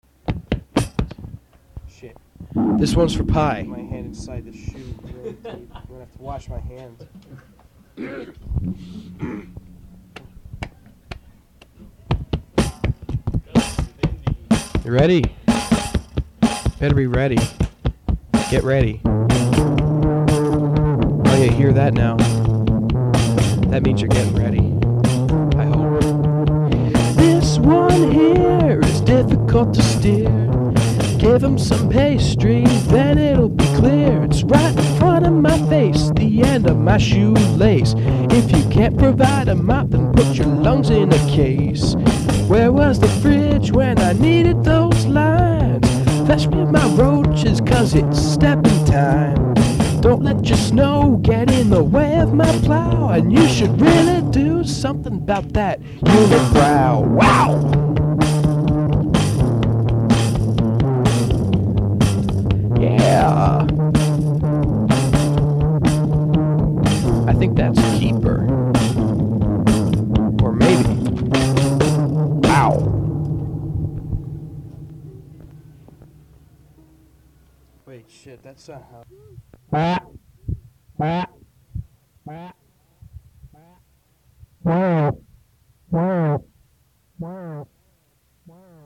a delightfully t. rex-ish romp.